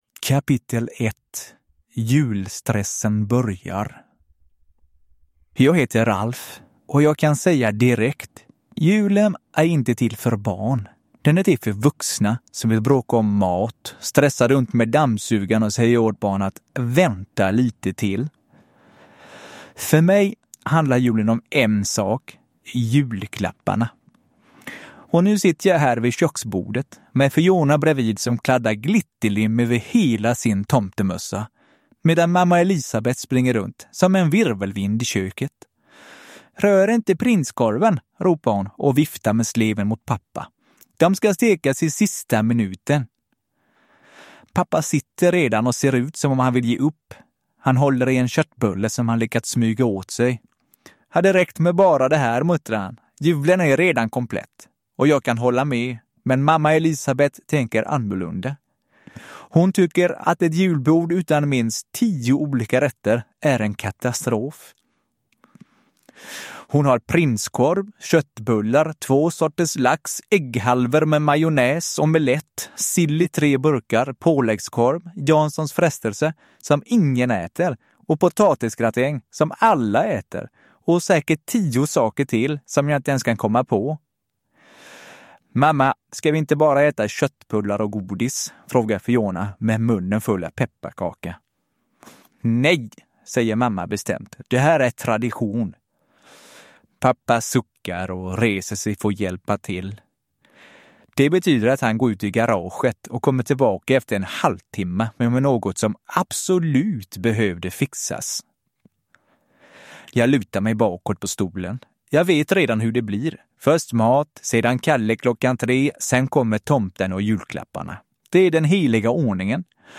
Kaouz på julafton – Ljudbok